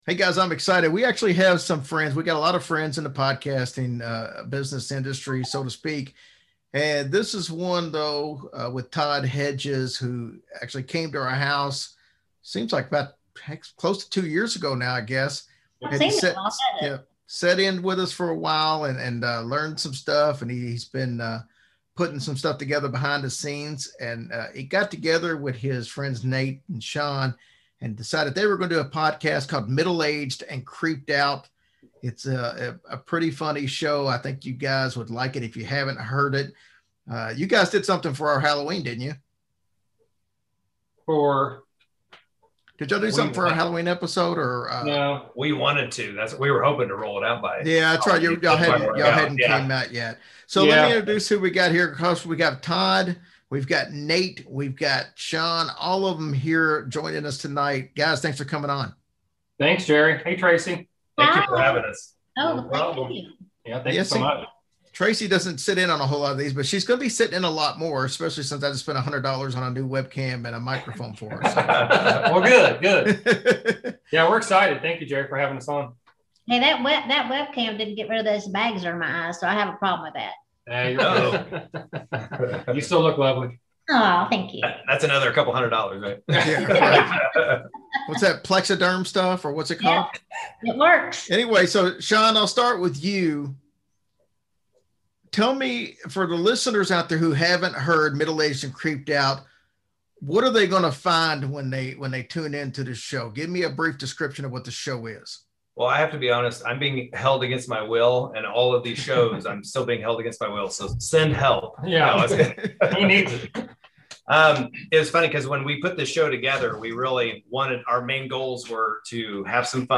Hillbilly Horror Stories Podcast Interview with MAACO (Bonus Episode)